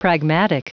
Prononciation du mot pragmatic en anglais (fichier audio)
Prononciation du mot : pragmatic